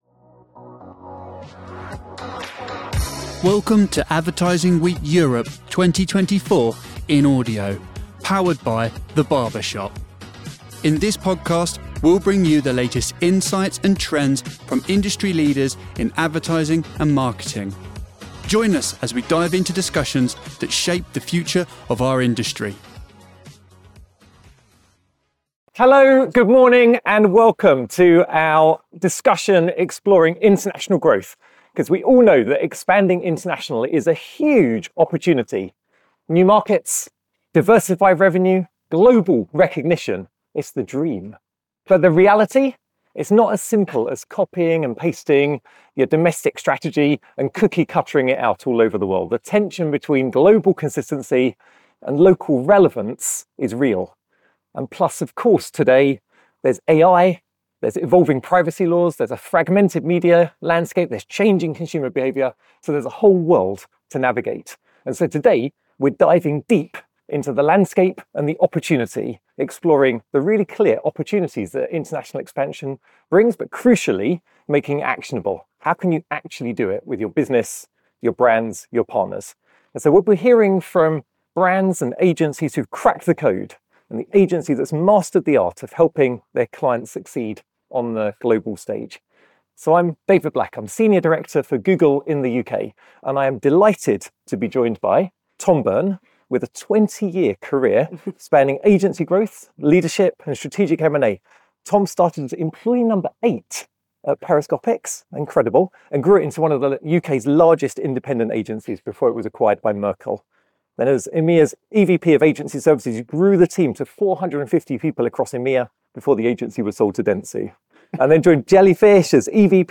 This session explores the key to scaling local campaigns for national and global impact. Industry leaders share insights on achieving seamless delivery across diverse markets, emphasising the importance of localised strategies within a unified media approach.